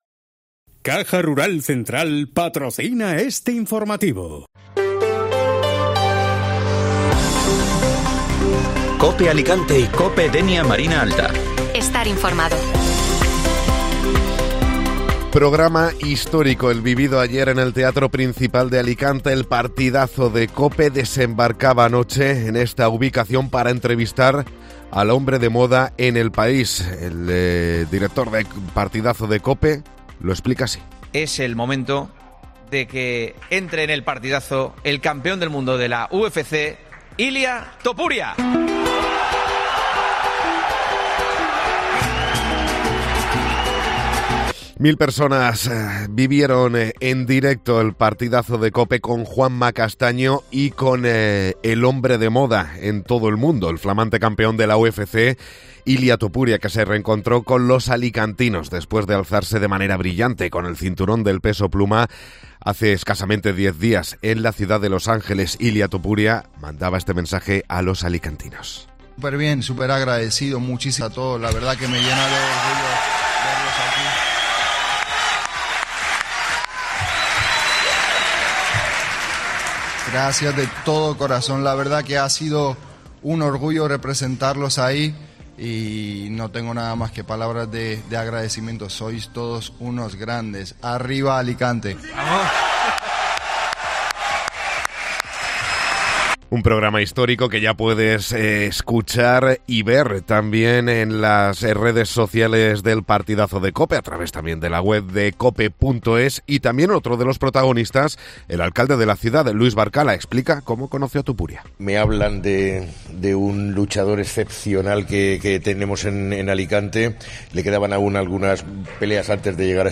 Todos los sonidos del Partidazo de Cope con Juanma Castaño desde el Teatro Principal de Alicante con Ilia Topuria como protagonista